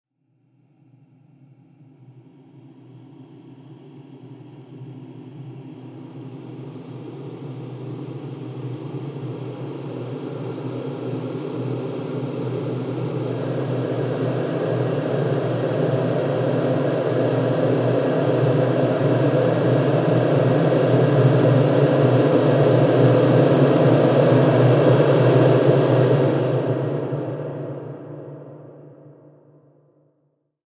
Slowly Rising Scary And Dramatic Sound Effect
Description: Slowly rising scary and dramatic sound effect. Creepy ambient background sound with slow build-up. Ideal for adding suspense, tension, or dramatic impact to your scene.
Slowly-rising-scary-and-dramatic-sound-effect.mp3